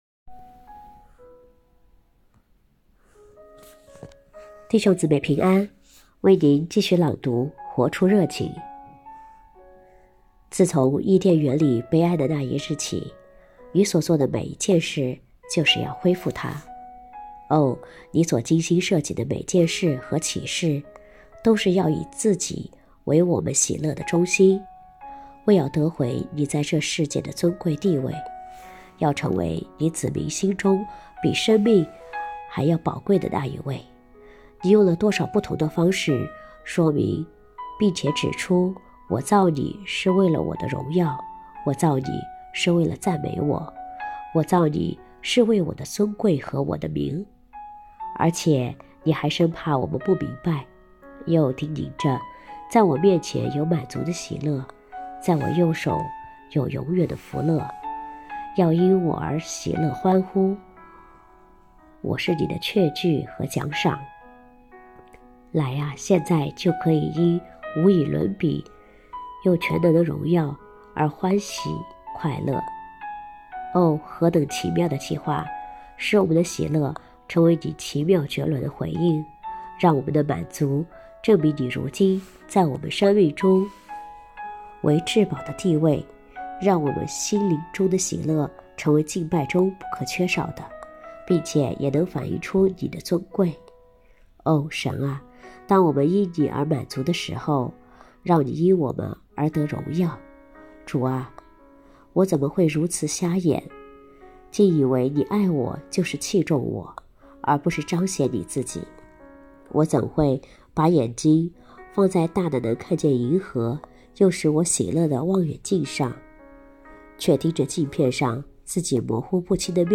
2024年10月31日 “伴你读书”，正在为您朗读：《活出热情》 欢迎点击下方音频聆听朗读内容 音频 https